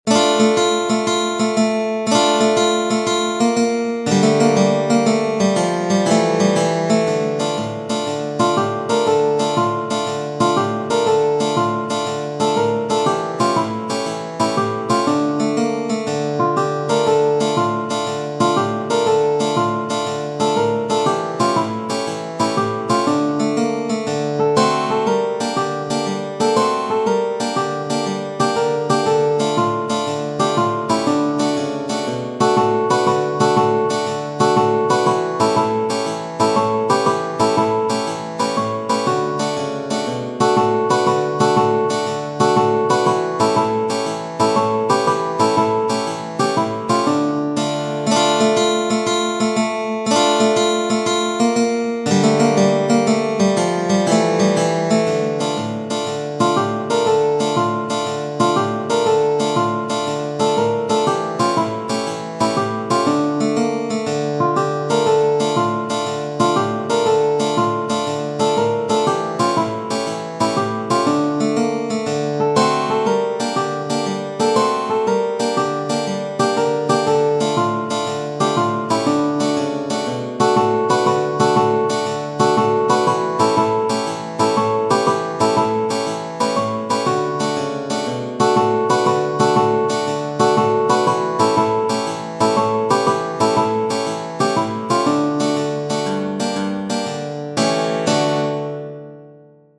Genere: Napoletane